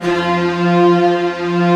Index of /90_sSampleCDs/Optical Media International - Sonic Images Library/SI1_Fast Strings/SI1_Not Fast